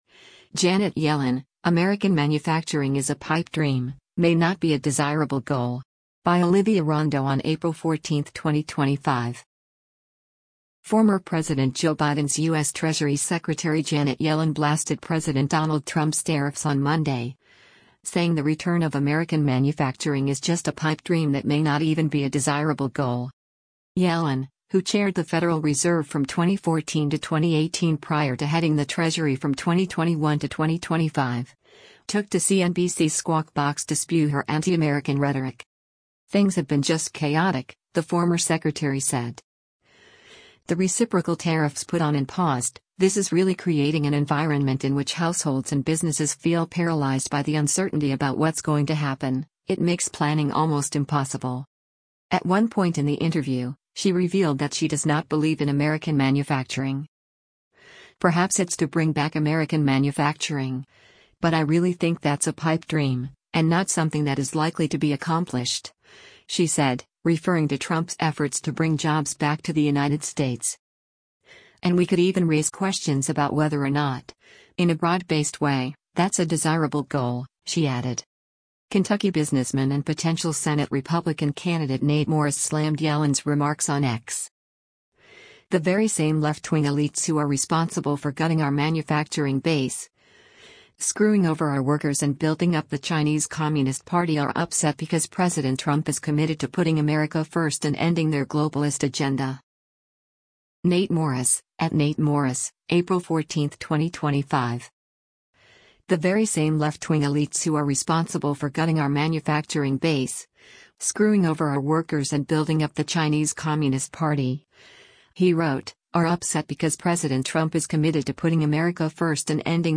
At one point in the interview, she revealed that she does not believe in “American manufacturing”: